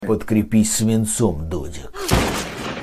• Качество: 128, Stereo
выстрел
голосовые
из игр